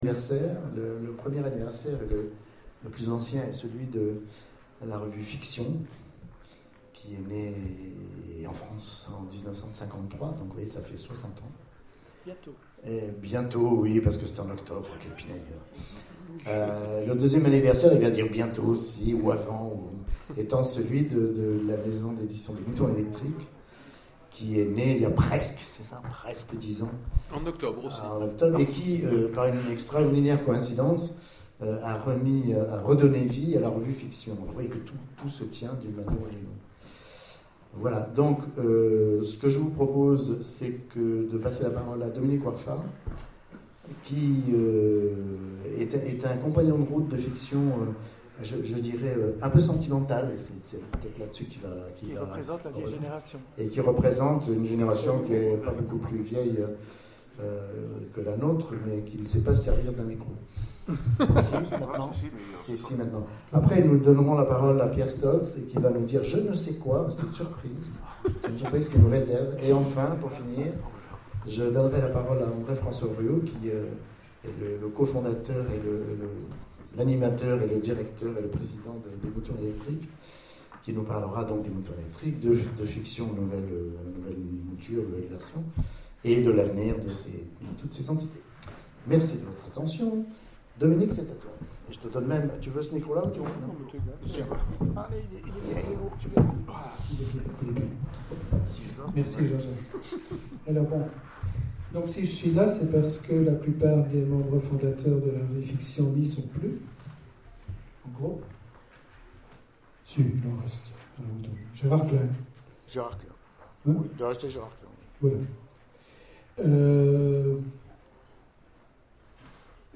Mots-clés Edition Conférence Partager cet article